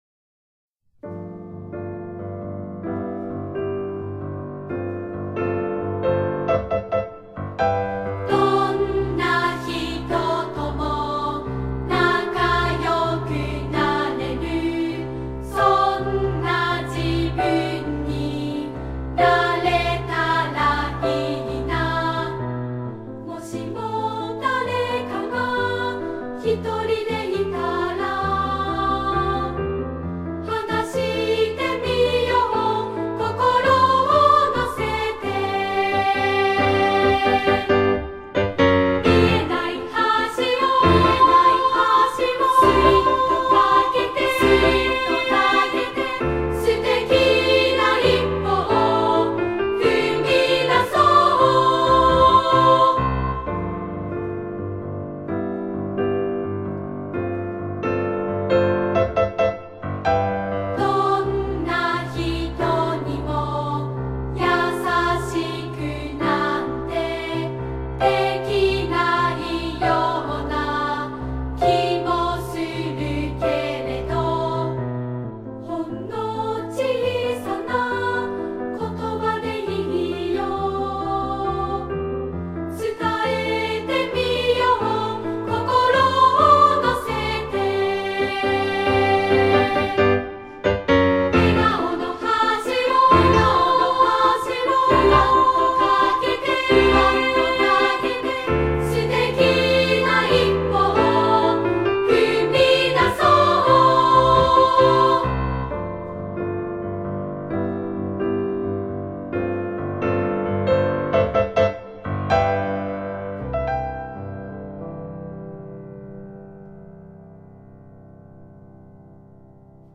さて，５月１４・１５日でお渡しした課題の中に 「すてきな一歩」 という曲があります。 すてきな一歩（歌唱） プリント学習をした後に聞いてみてくださいね♪